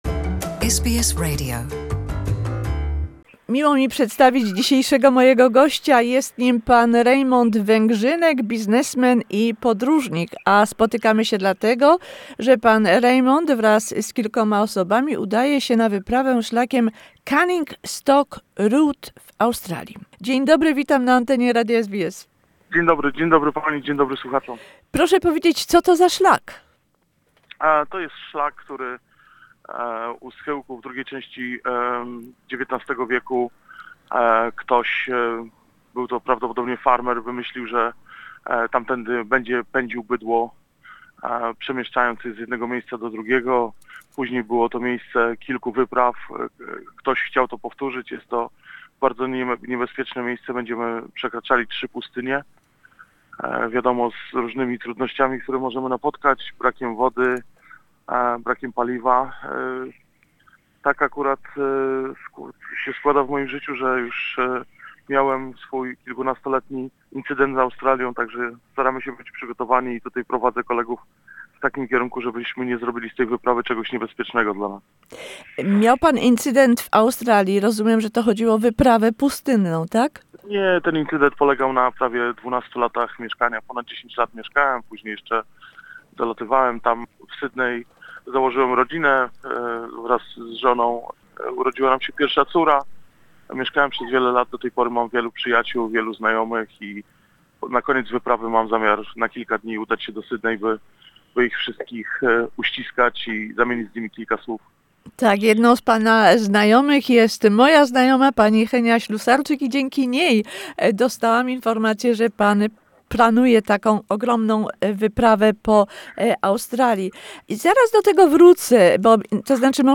The DriveFar Expedition by nine Polish adventurers on the iconic 4.5 thousands kilometer Canning Stock Route.. An interview